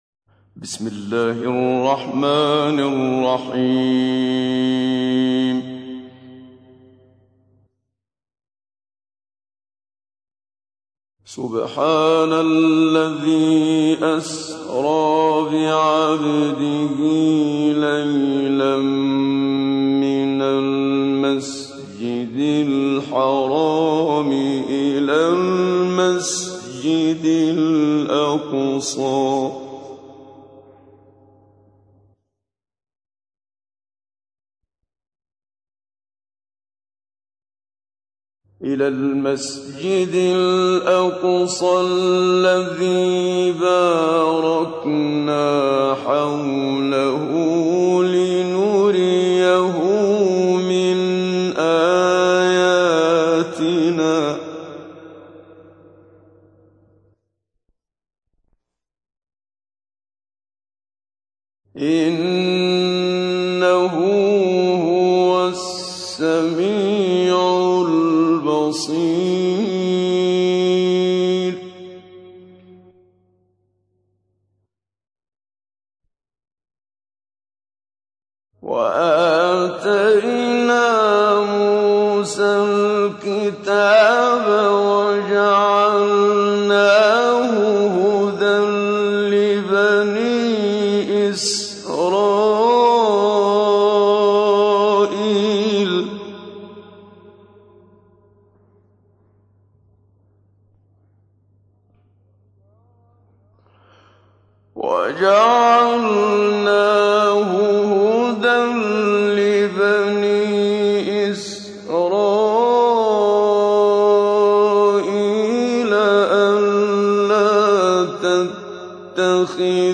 تحميل : 17. سورة الإسراء / القارئ محمد صديق المنشاوي / القرآن الكريم / موقع يا حسين